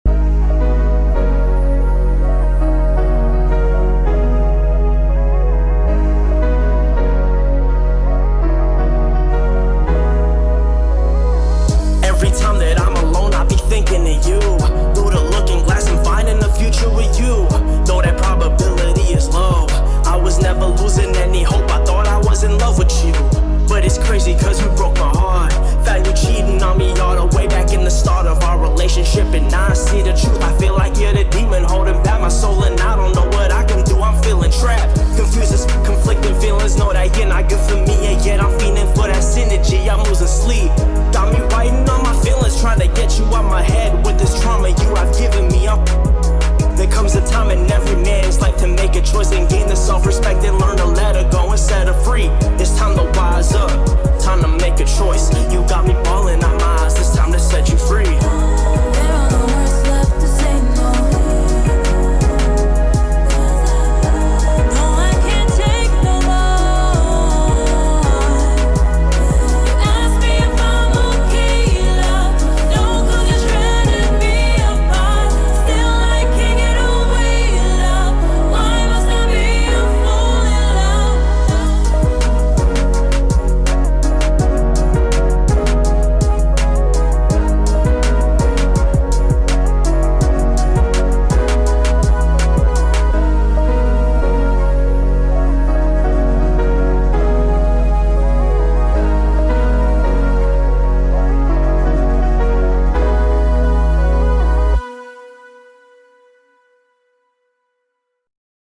BPM165
Audio QualityPerfect (High Quality)
2023-04-06: The song now uses the clean version.